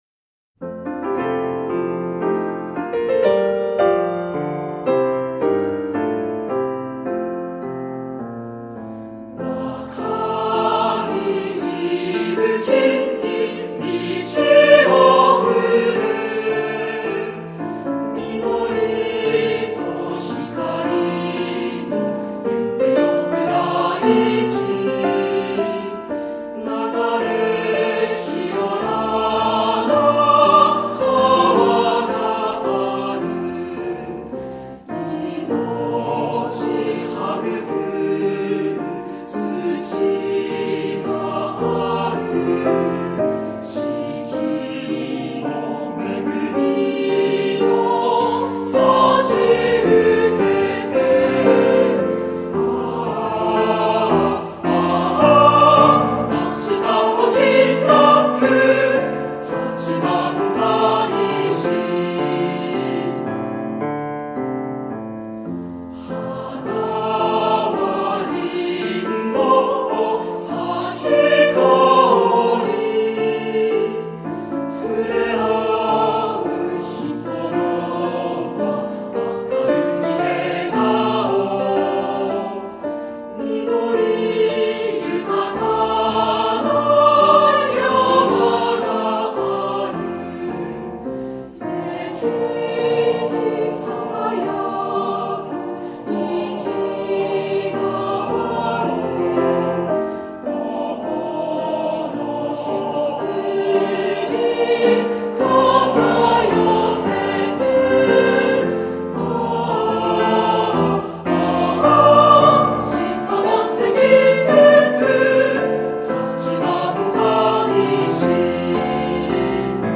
• 七五調を基本としながらも、転調もあり起伏がある。
男女混声 [その他のファイル／3.14MB]
「農と輝の大地」というキャッチフレーズを作品に活かし、明るく、あたたかく、元気の出る歌を心がけました。
曲調は一貫してヘ長調ですが、同じ調の中で、色彩的な工夫をしています。